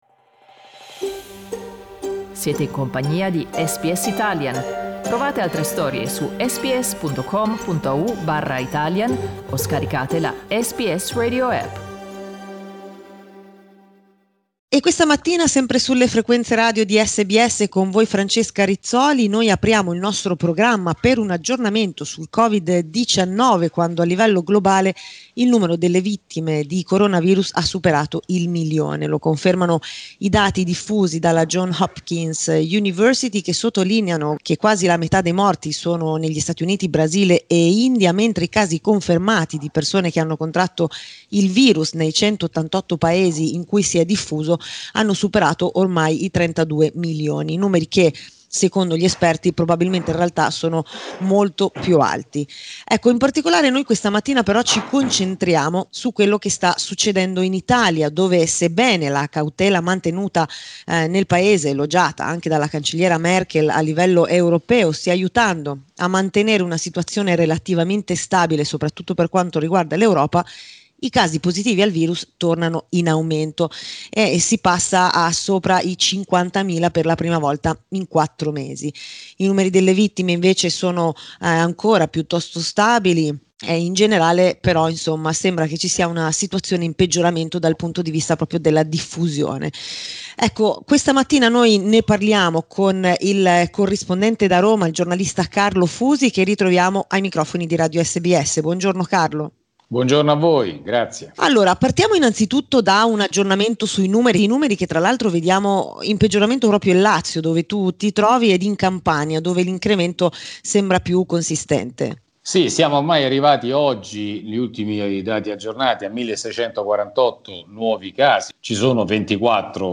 in collegamento da Roma